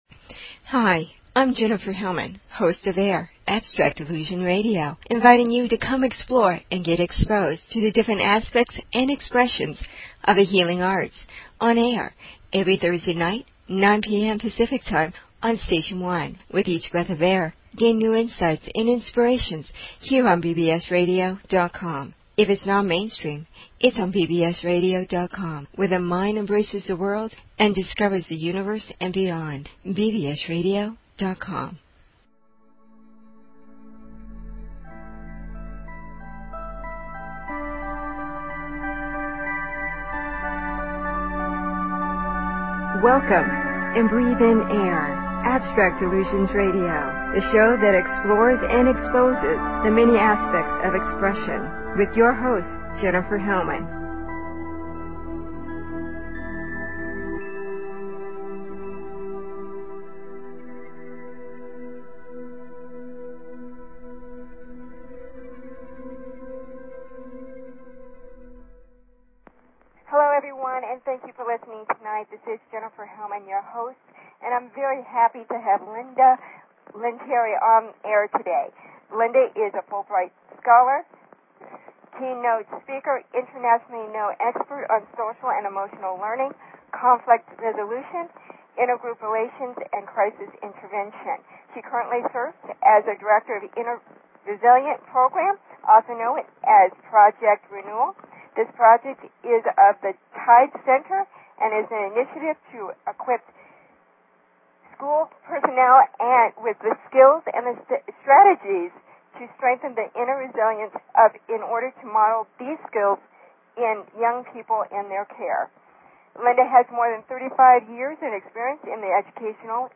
Talk Show Episode, Audio Podcast, Abstract_Illusion_Radio and Courtesy of BBS Radio on , show guests , about , categorized as
(this will be a pre-recorded show)